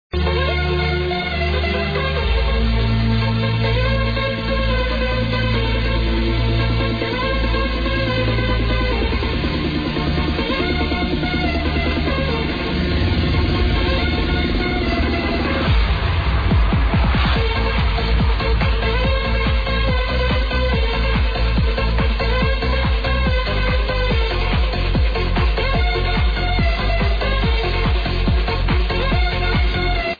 need a name of this well catchy trancer